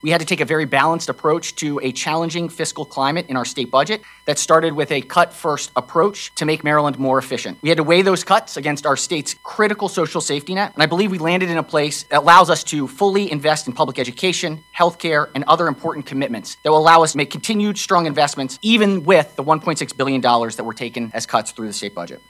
In one of the final Maryland Democratic Senate press briefings on Friday, Senate President Bill Ferguson looked back on the past three months, saying this has been the most difficult session in his years in Annapolis, but he is pleased with the work done…